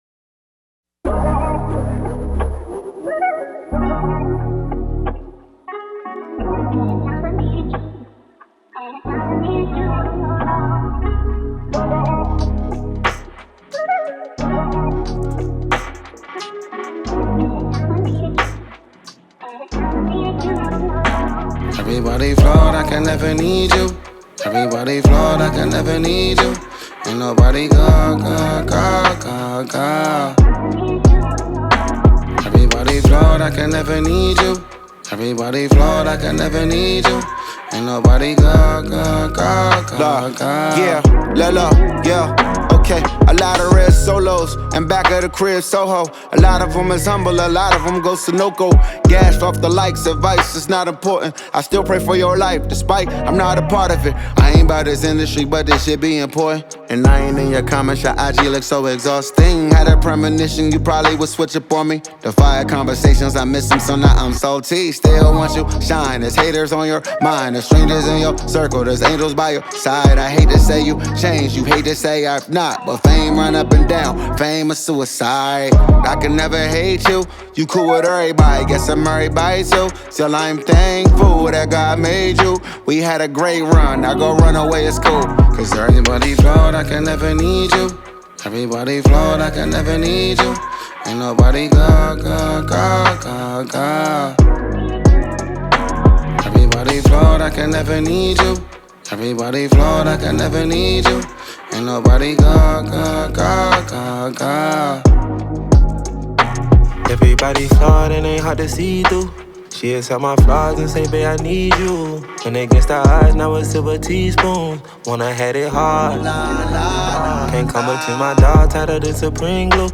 Veteran Nigerian-American rapper
melodic break up ballad